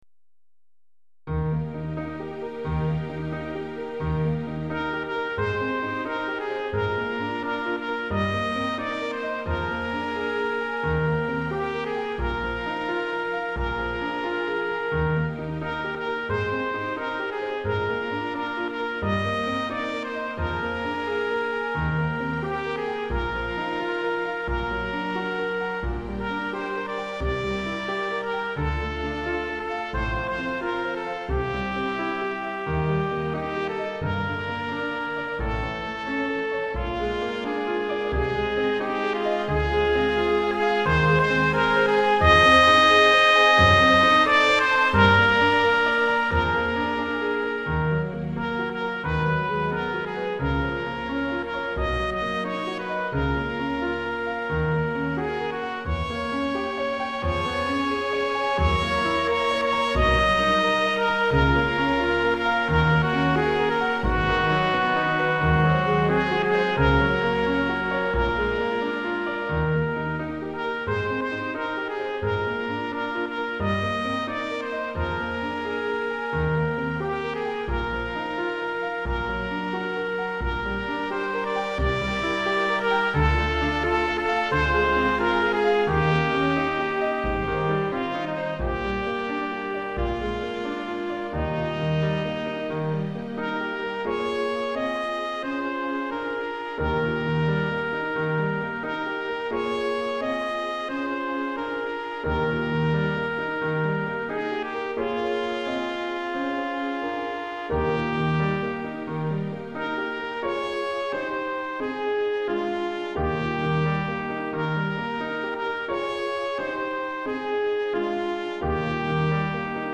Répertoire pour Musique de chambre - Trompette Sib